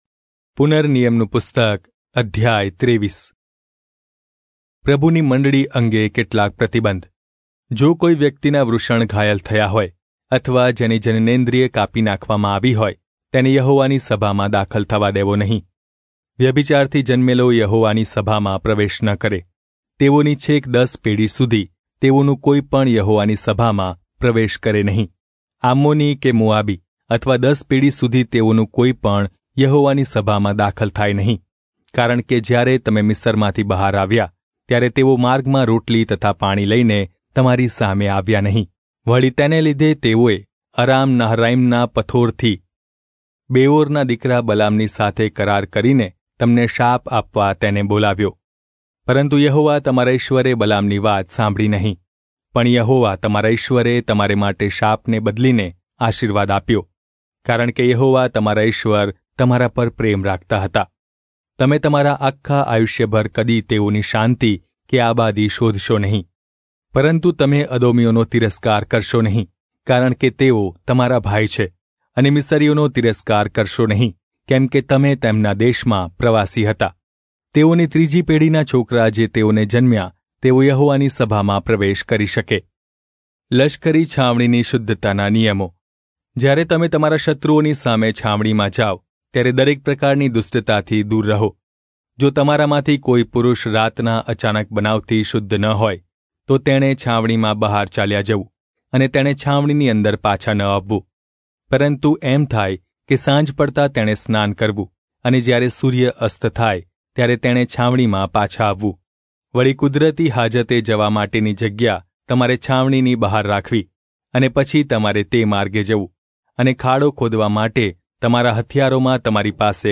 Gujarati Audio Bible - Deuteronomy 30 in Irvgu bible version